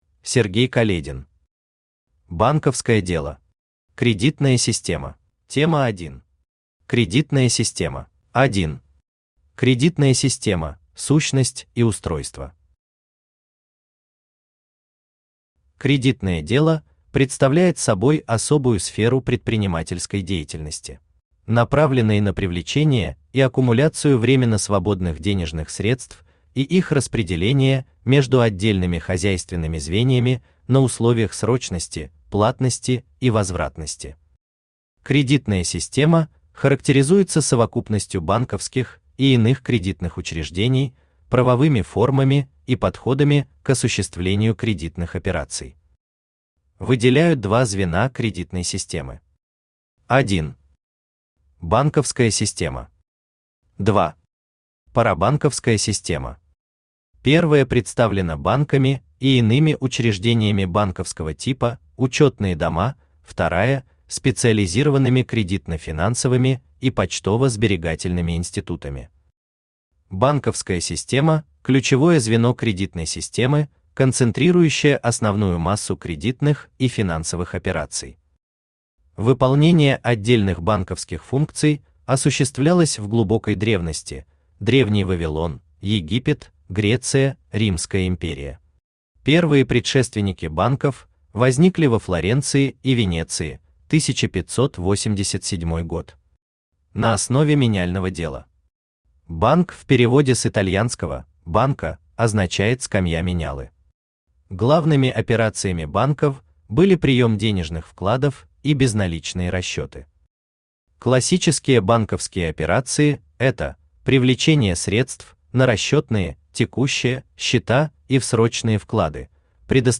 Аудиокнига Банковское дело. Кредитная система | Библиотека аудиокниг
Кредитная система Автор Сергей Каледин Читает аудиокнигу Авточтец ЛитРес.